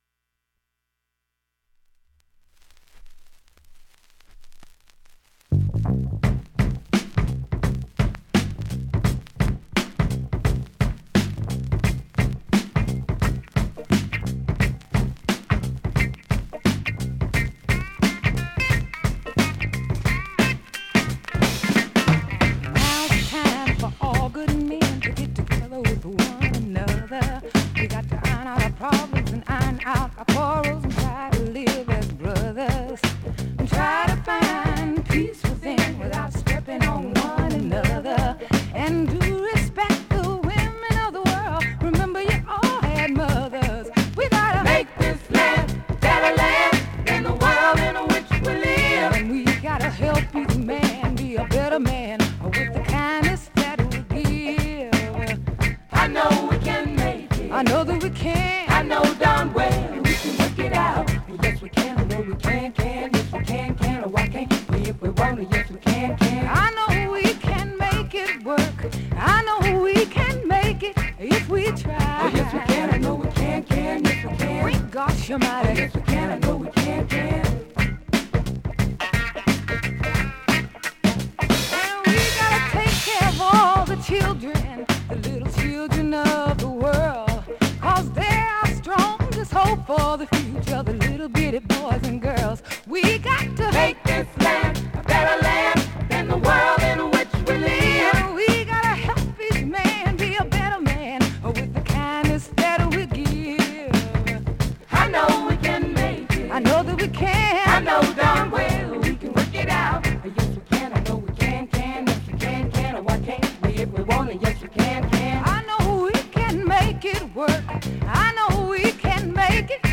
現物の試聴（両面すべて録音時間8分56秒）できます。
名ブレイクから始るレディファンク